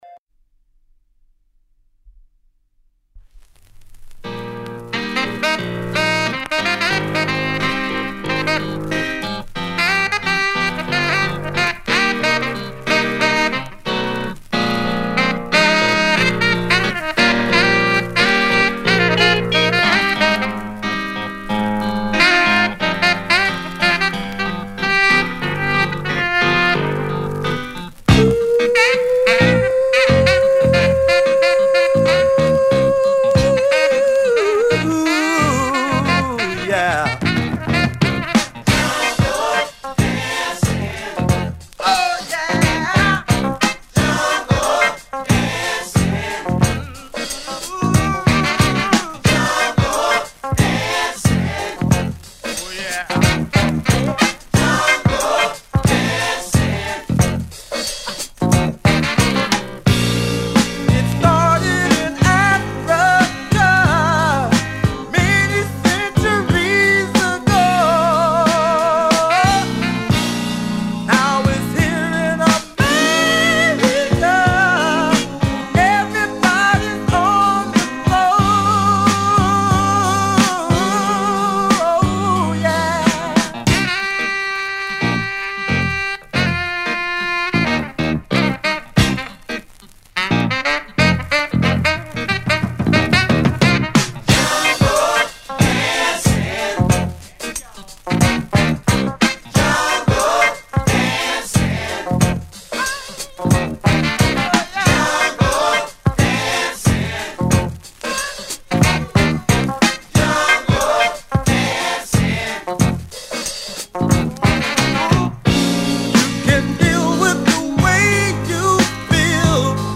Il sabato del villaggio... una trasmissione totalmente improvvisata ed emozionale. Musica a 360°, viva, legata e slegata dagli accadimenti.